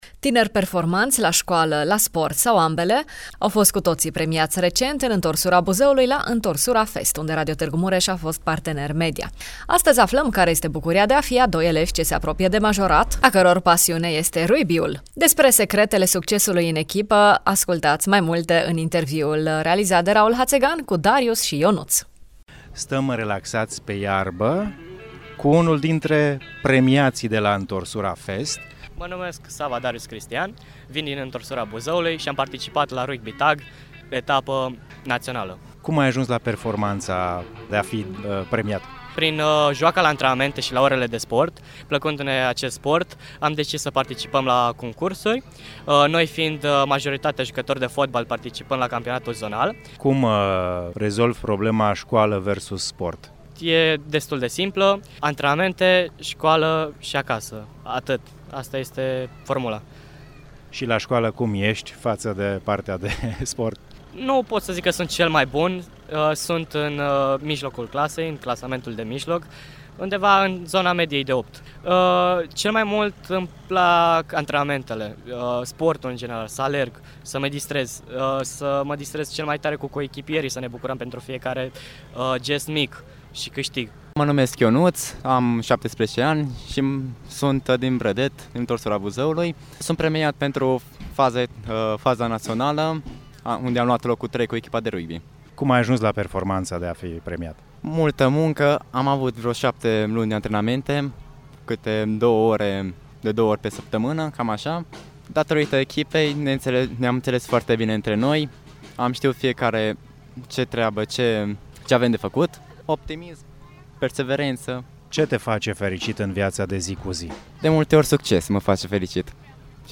Doi tineri sportivi ne împărtășesc azi din satisfacțiile ce vin după efort susținut și concentrare intensă asupra a ceea ce îți dorești: